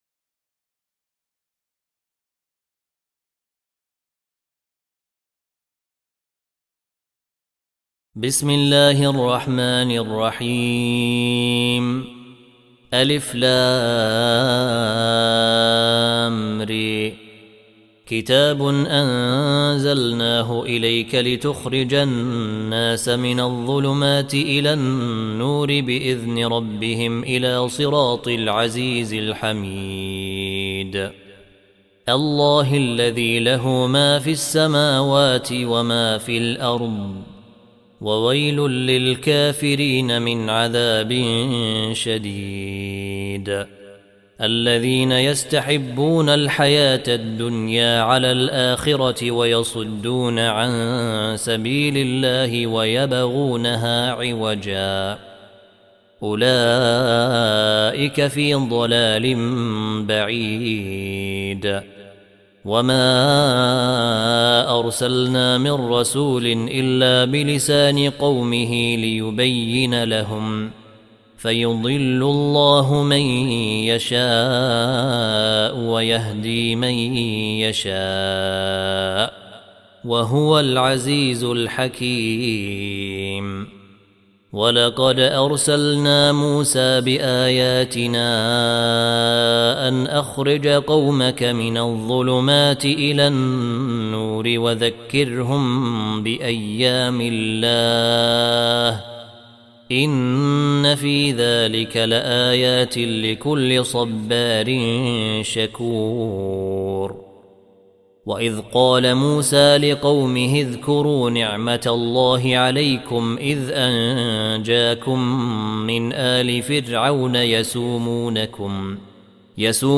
14. Surah Ibrah�m سورة إبراهيم Audio Quran Tarteel Recitation
Surah Repeating تكرار السورة Download Surah حمّل السورة Reciting Murattalah Audio for 14.